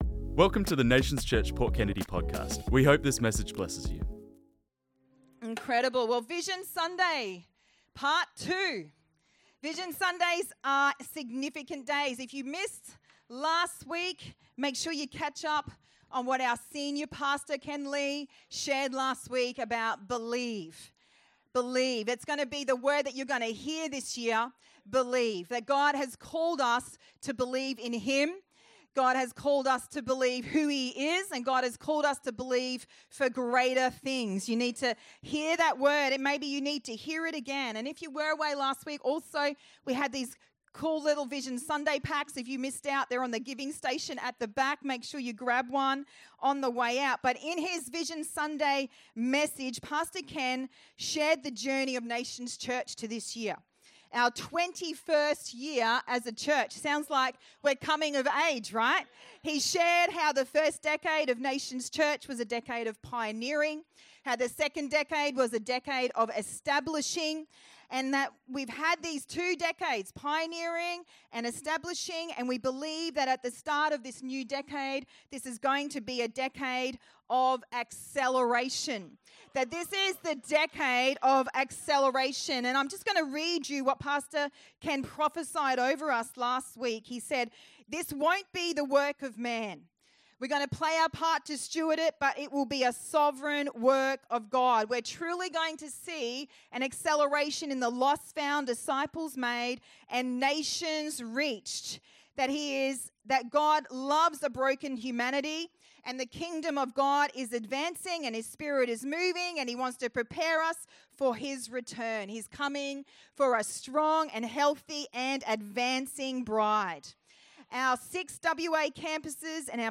This message was preached on Sunday 23rd February 2025